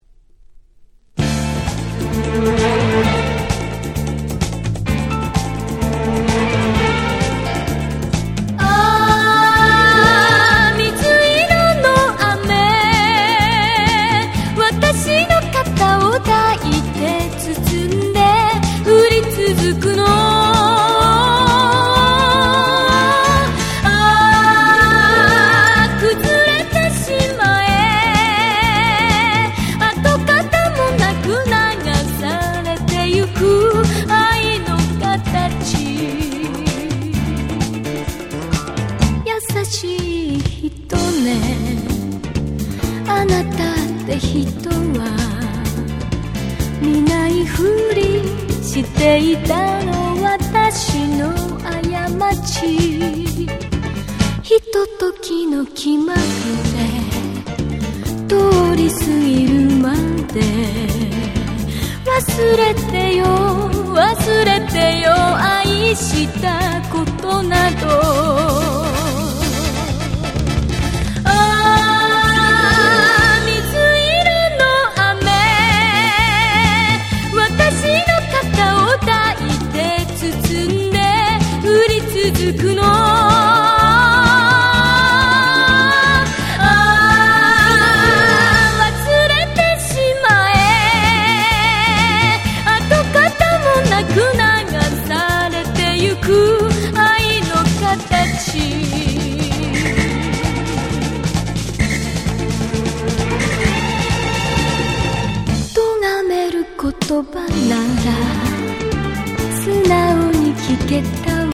78' Super Nice 昭和歌謡 !!
J-Pop 懐メロ